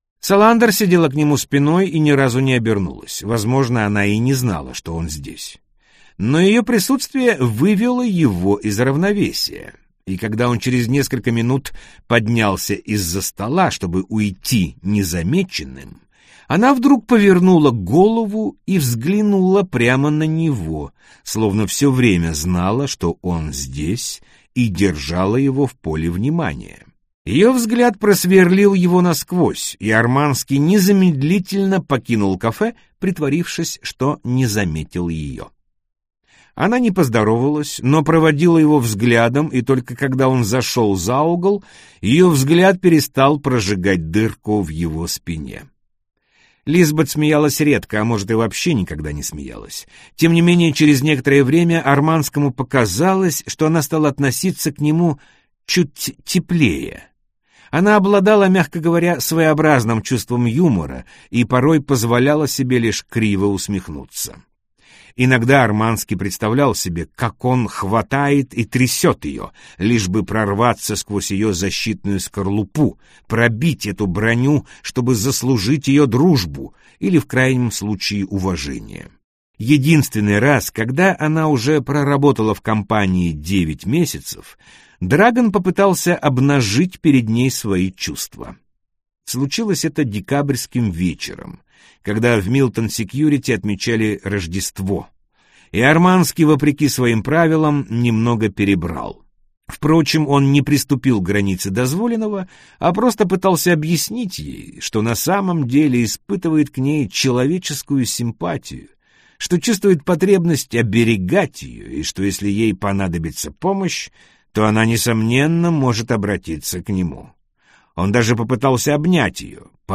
Аудиокнига Девушка с татуировкой дракона - купить, скачать и слушать онлайн | КнигоПоиск